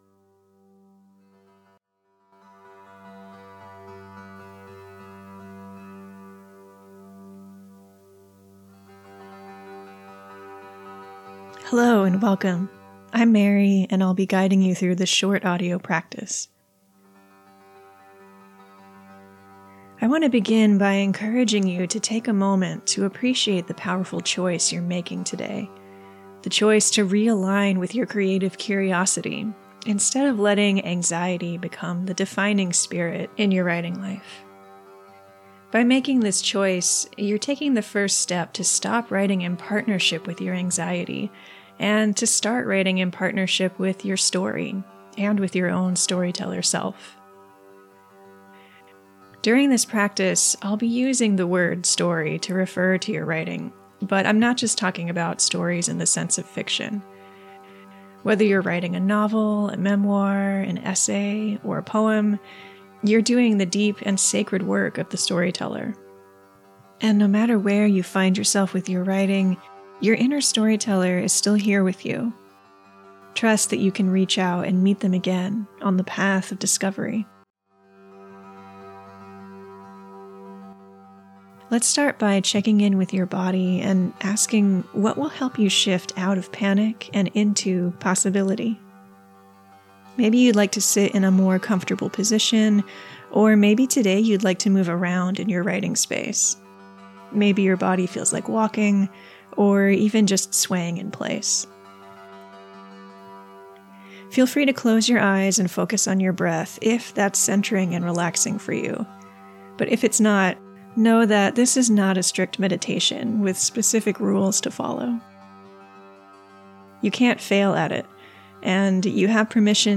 This short guided experience will bring you back to center, and it will help you uncover immediate, tangible threads of inspiration to get the words flowing again.